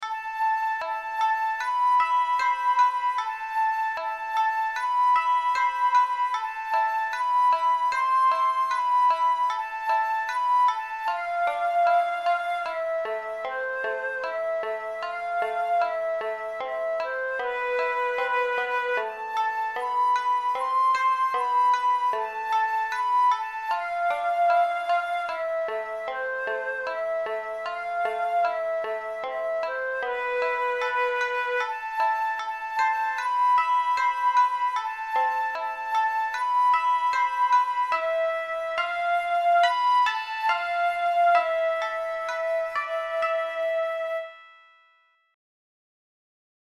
Traditiona Japanese song